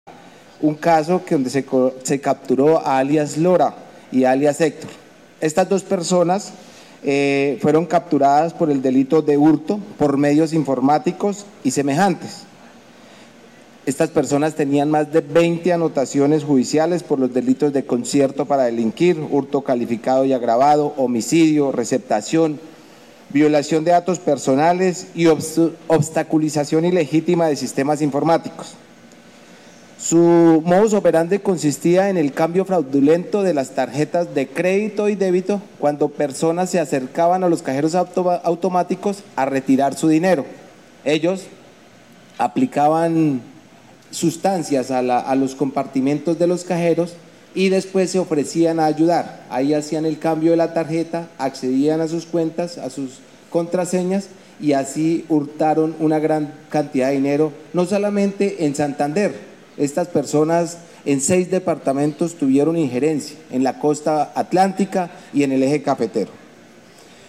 Coronel Néstor Arévalo, comandante de la policía de Santander